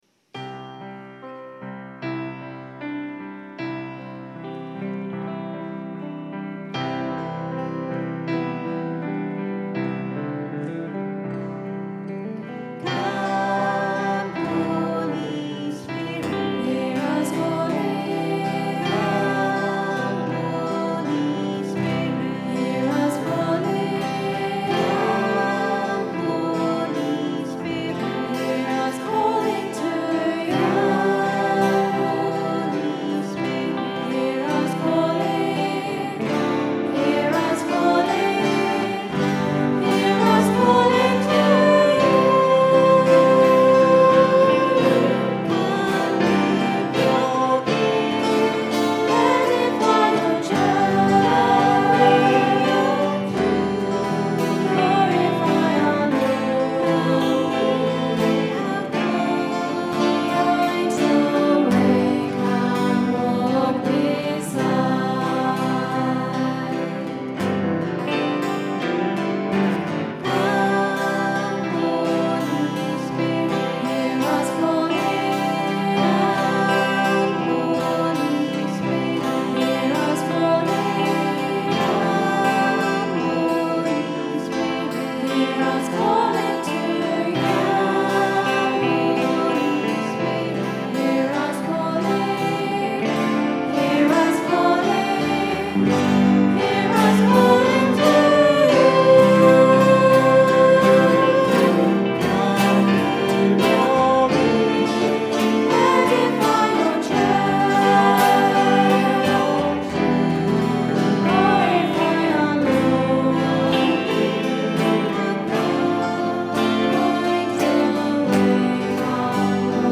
Recorded at 10 am Mass, 5th May 2013 on a Zoom H4 digital stereo recorder.